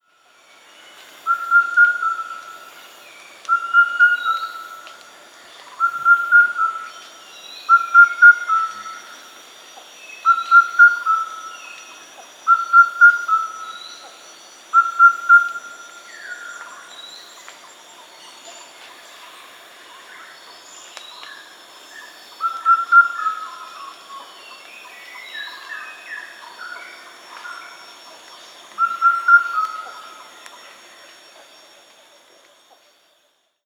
We have a four-note song in this case.